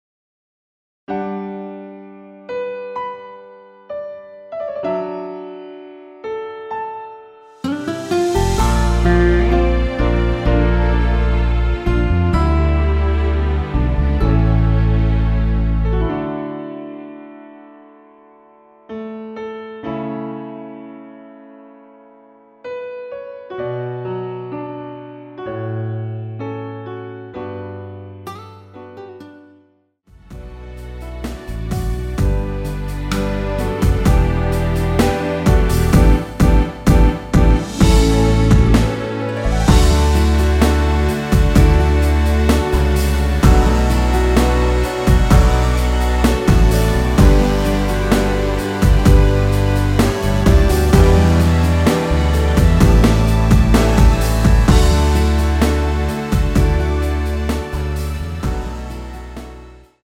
MR입니다.
앞부분30초, 뒷부분30초씩 편집해서 올려 드리고 있습니다.
중간에 음이 끈어지고 다시 나오는 이유는